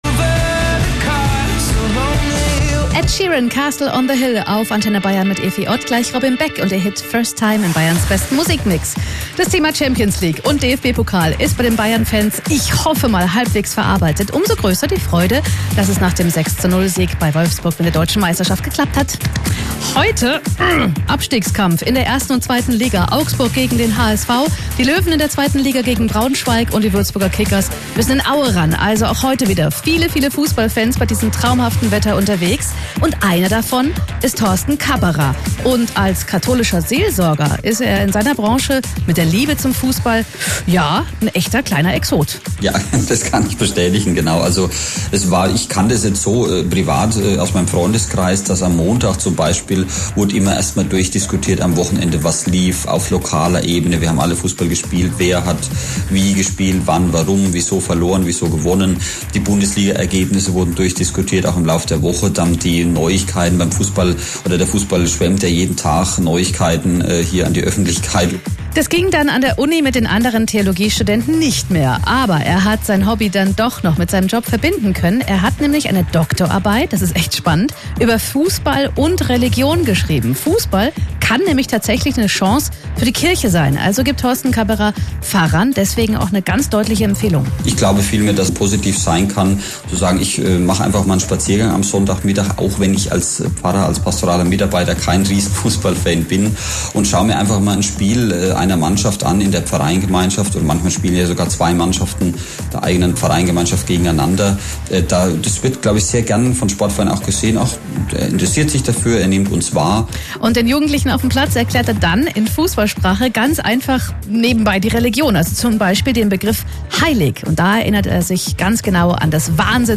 antenne-bayern_interview_juni-2017.mp3